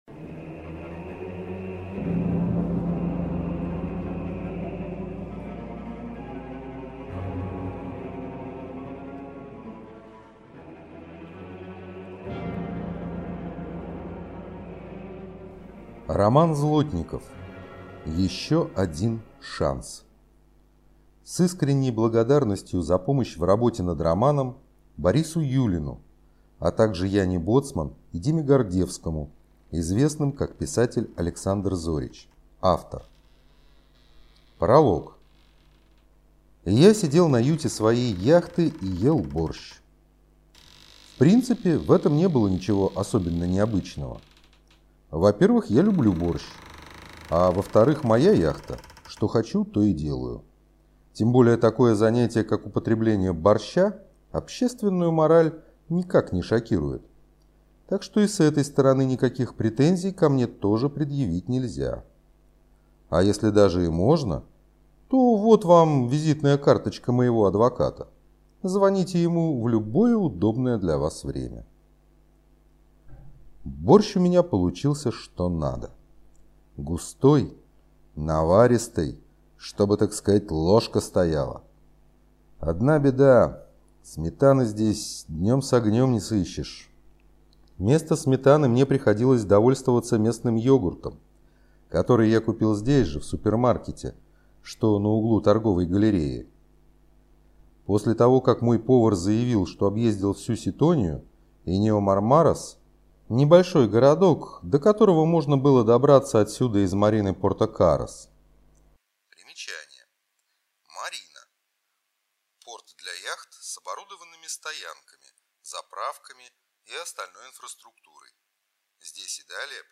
Аудиокнига Еще один шанс…